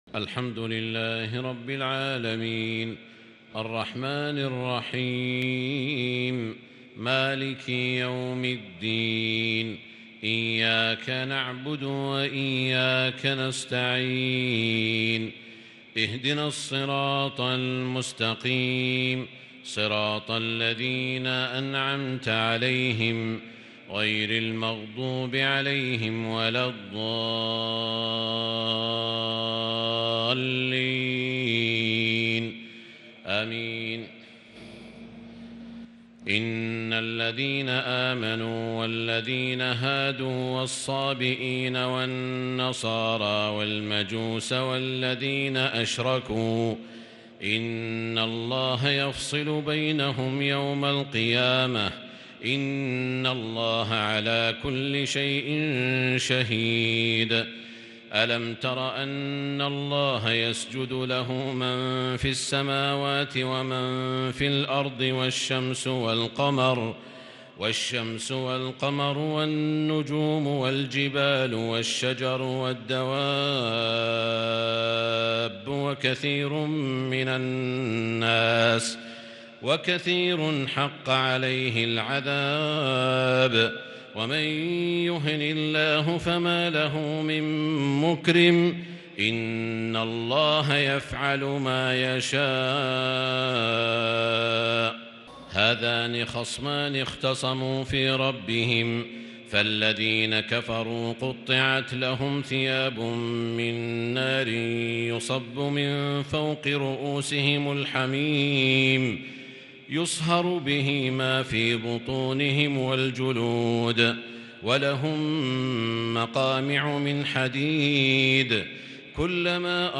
تراويح ليلة 22 رمضان 1442هـ من سورة الحج {17_ 78 }المؤمنون {1-22} | Taraweeh 22 th night Ramadan 1442H > تراويح الحرم المكي عام 1442 🕋 > التراويح - تلاوات الحرمين